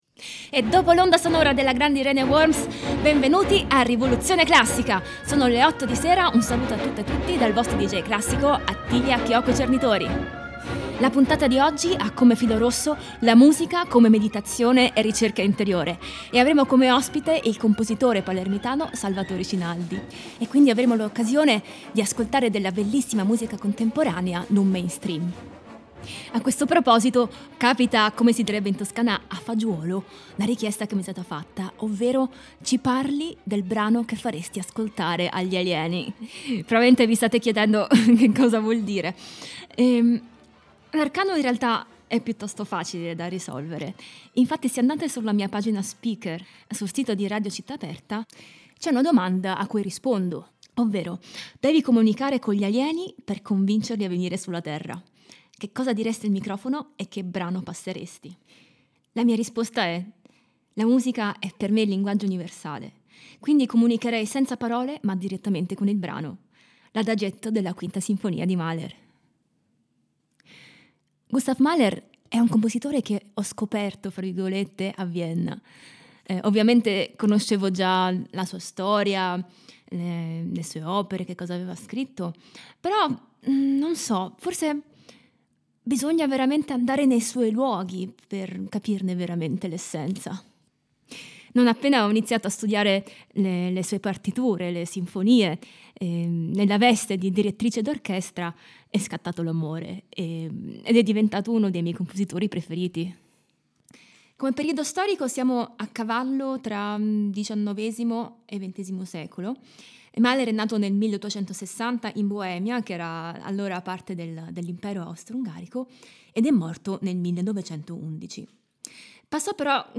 pianoforte e orchestra